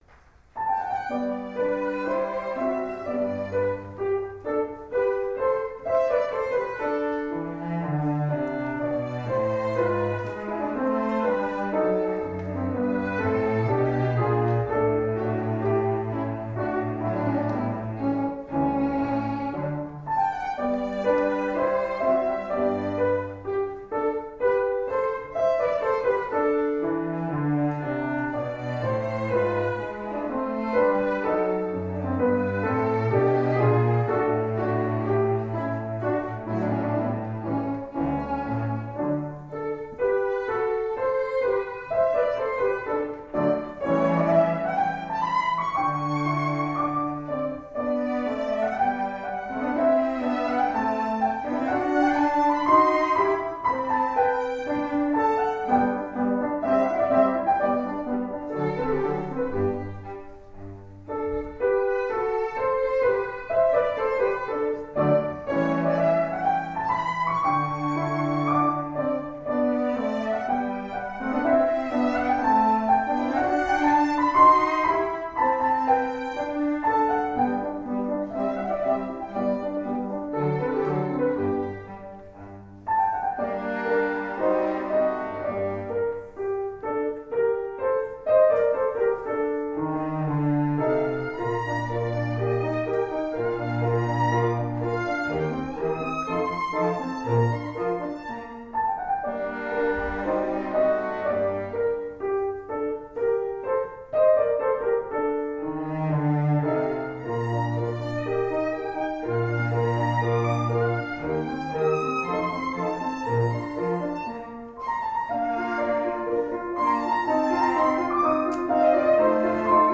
Instrument: Piano Trio
Style: Classical
violin
cello
piano